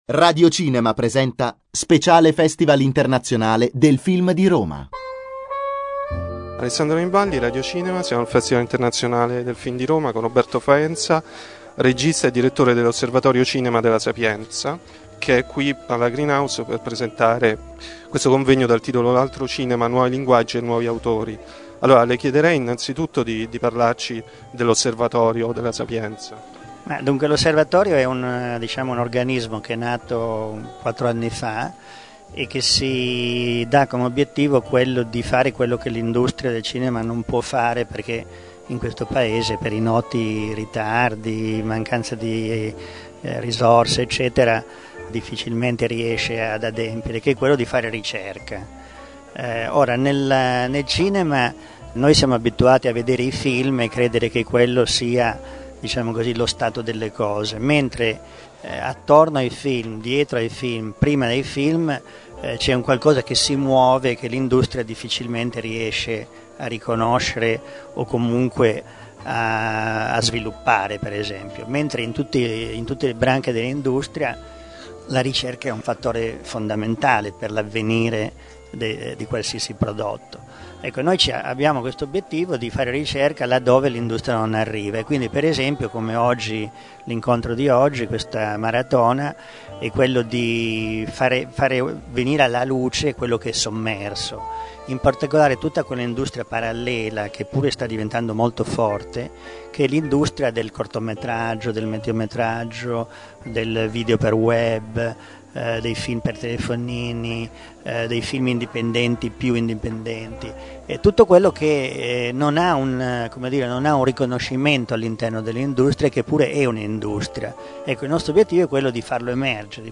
podcast_Roma_2010_Intervista_Roberto_Faenza.mp3